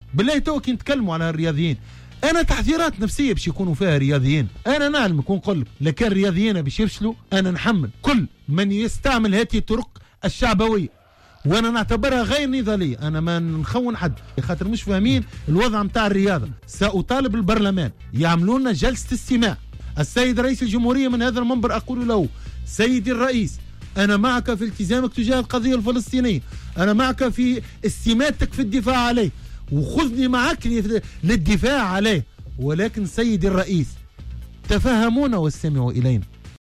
أوضح رئيس اللجنة الوطنية الأولمبية محرز بوصيان لدى حضوره في حصة "قوول" أن بعض الممارسات الشعبوية التي يقوم بها عدد من السياسيين قد يؤثر سلبا على مردود الرياضيين في أولمبياد طوكيو 2020.